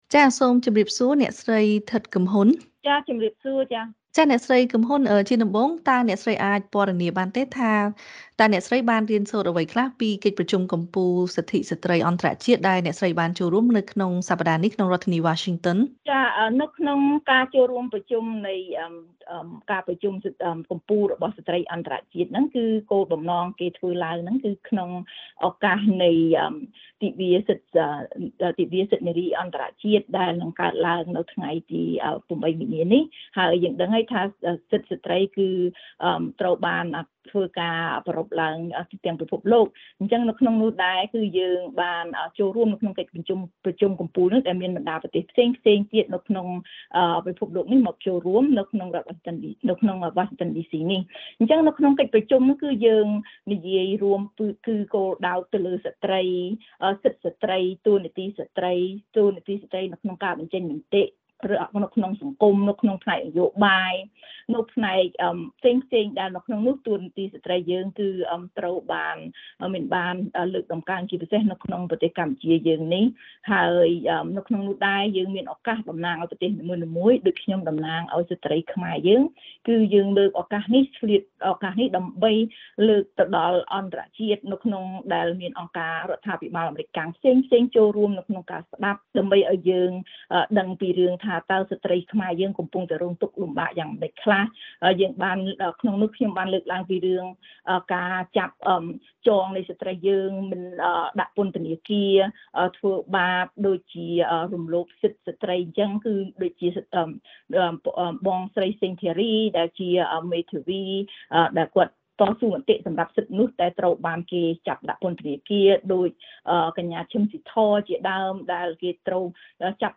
បទសម្ភាសន៍ VOA៖ ការលើកកម្ពស់ស្ត្រីក្នុងនយោបាយនិងការឈឺឆ្អាលរឿងសង្គមជួយឱ្យស្ត្រីរស់ក្នុងសន្តិភាពពិតប្រាកដ